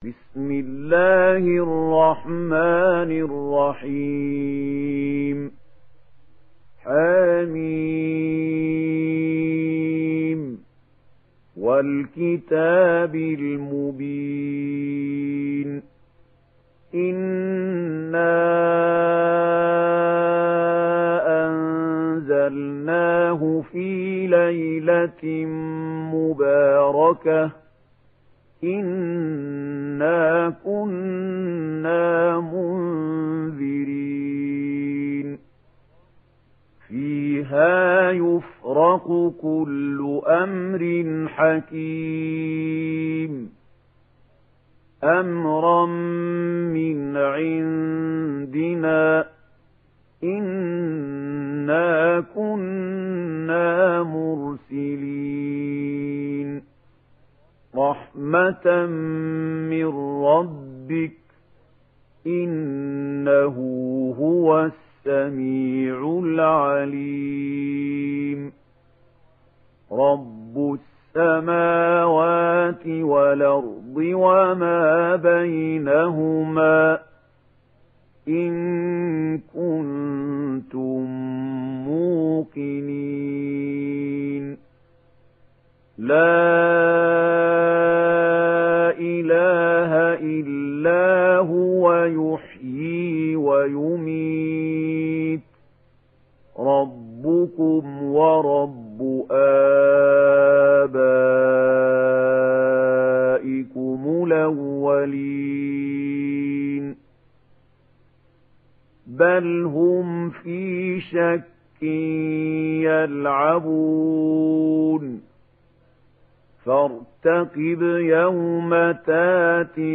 Duhan Suresi İndir mp3 Mahmoud Khalil Al Hussary Riwayat Warsh an Nafi, Kurani indirin ve mp3 tam doğrudan bağlantılar dinle